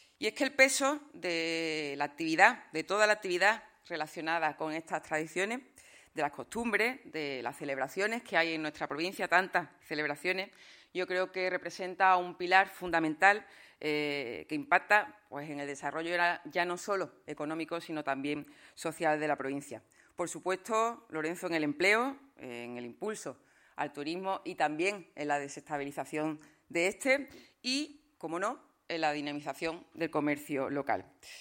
Almudena Martínez participa en Jerez en el I Foro Comercio sobre ‘La economía en torno a las tradiciones’ organizado por Tribuna Económica